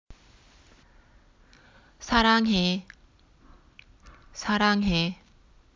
サランへ
さらんへ.mp3